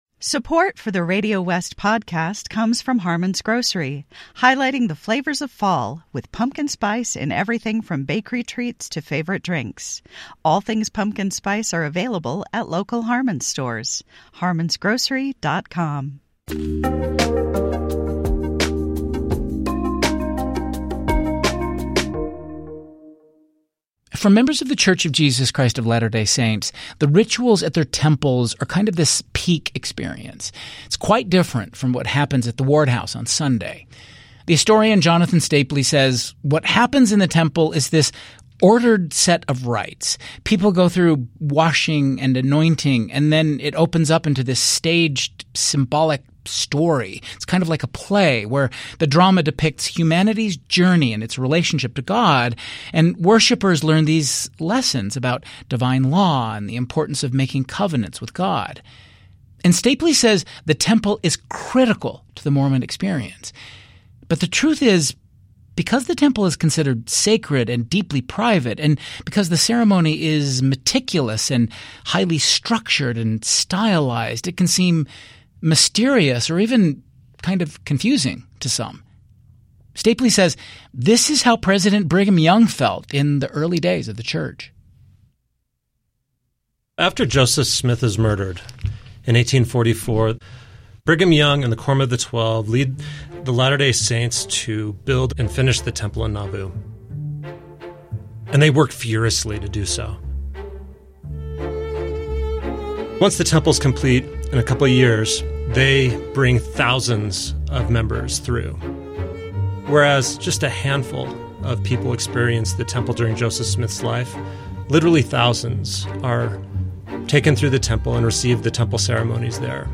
1 The Tell: A Conversation About Healing and Re-Parenting Oneself with Amy Griffin 53:09 Play Pause 14d ago 53:09 Play Pause Play later Play later Lists Like Liked 53:09 In this week’s episode, I’m joined by Amy Griffin for a deeply personal conversation about what it means to re-parent yourself—and how that inner work quietly transforms your parenting. We talk about the pressure to be perfect, the role of control and trust in family relationships, and how healing your own story can create more safety for your kids…